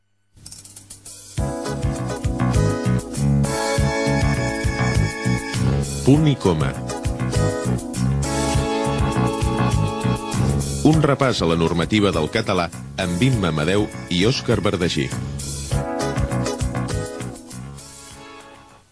Careta del programa
FM